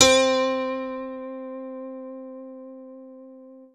Keys (5).wav